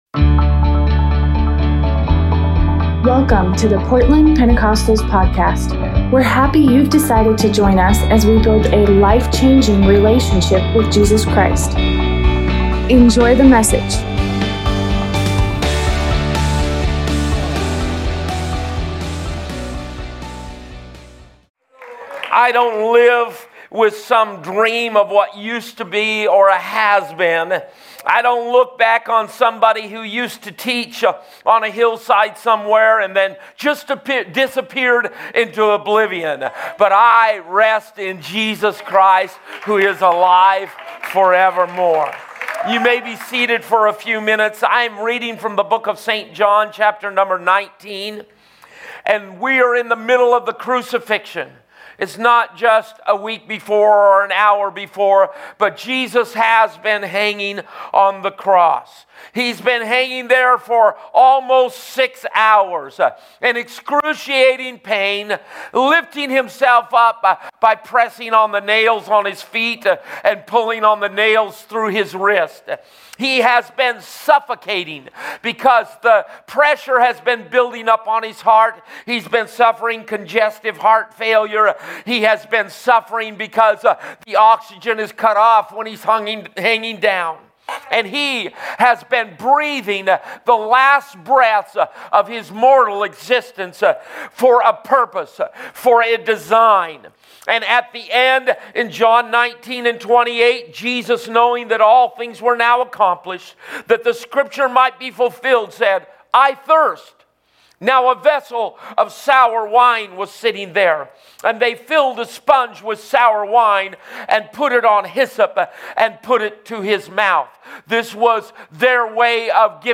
Easter Sunday sermon